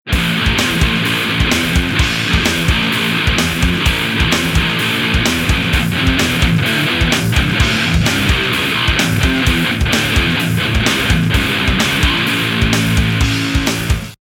Сухо, жирно, почти не пластмассово, коммерчески.